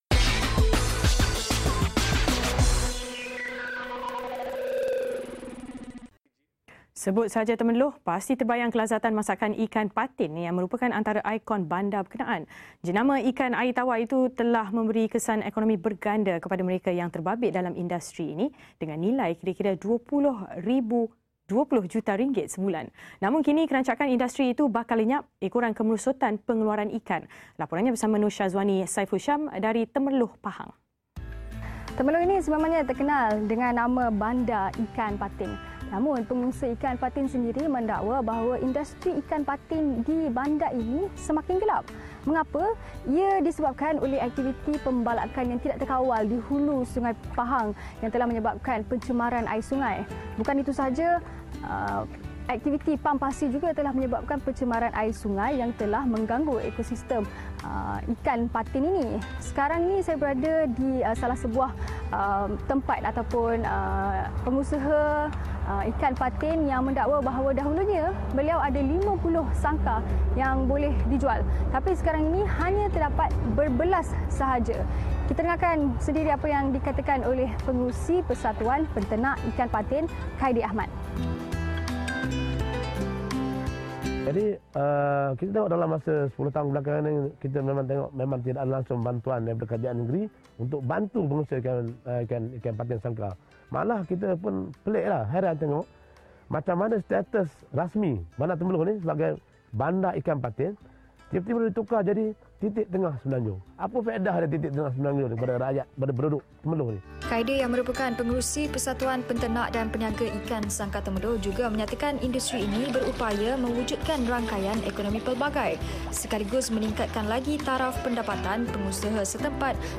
Laporan